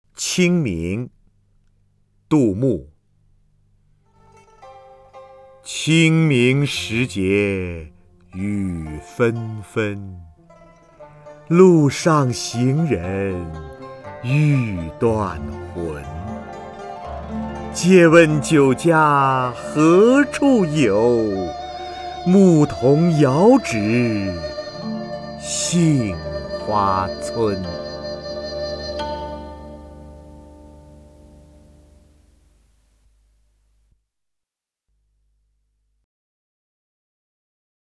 瞿弦和朗诵：《清明》(（唐）杜牧) (右击另存下载) 清明时节雨纷纷， 路上行人欲断魂。